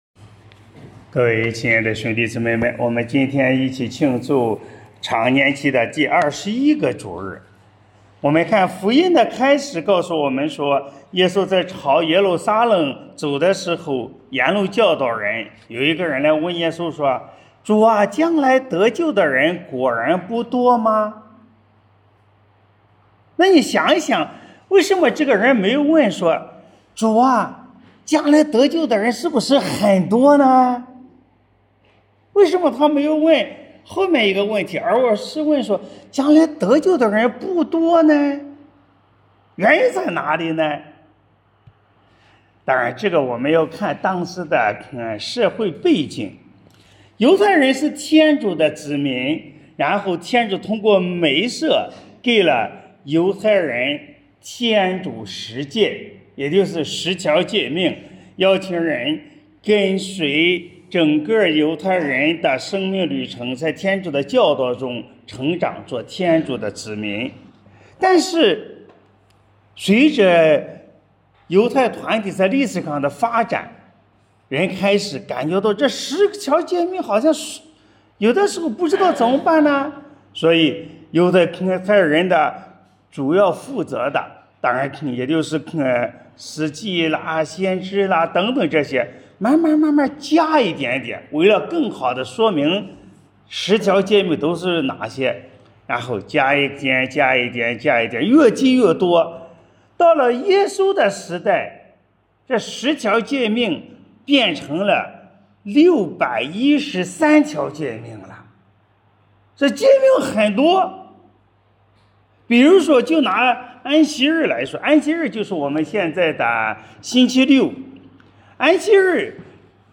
【主日证道】| 得救之道通天堂（丙-常年期第21主日）